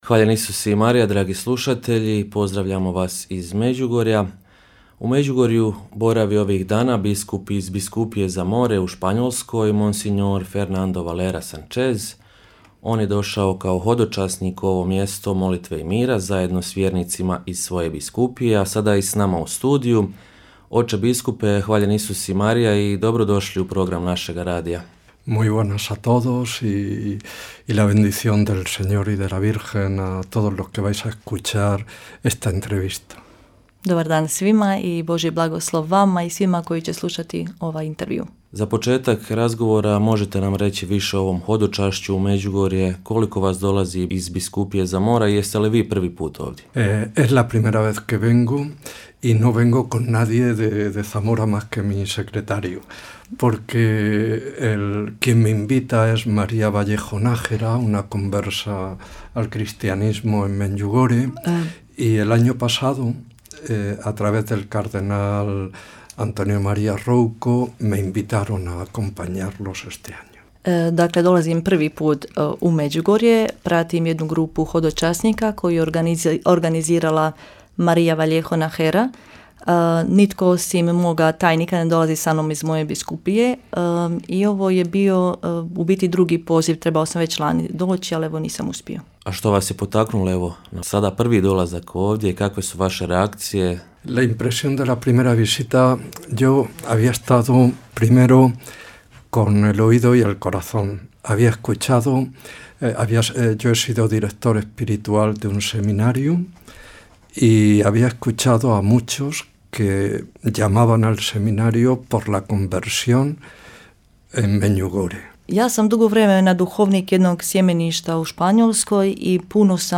Biskup Valera, za vrijeme boravka u Međugorju, dao je i intervju za Radiopostaju Mir.